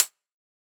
Index of /musicradar/ultimate-hihat-samples/Hits/ElectroHat C
UHH_ElectroHatC_Hit-04.wav